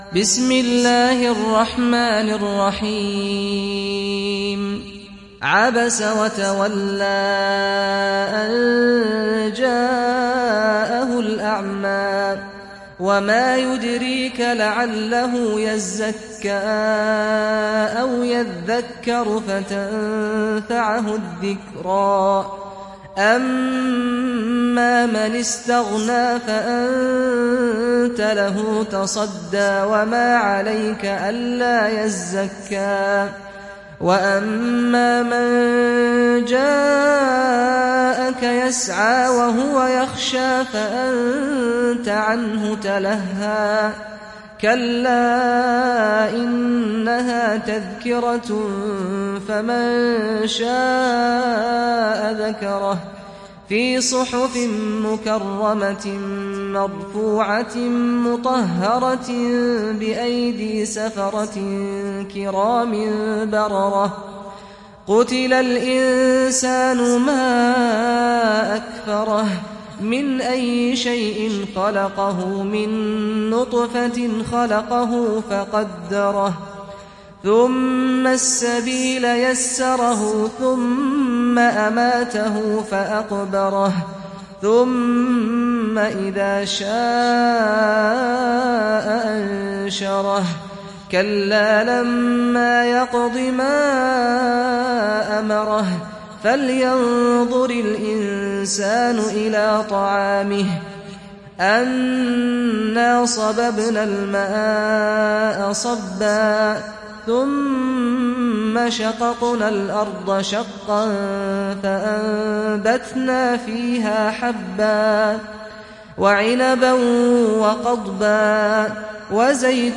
تحميل سورة عبس mp3 بصوت سعد الغامدي برواية حفص عن عاصم, تحميل استماع القرآن الكريم على الجوال mp3 كاملا بروابط مباشرة وسريعة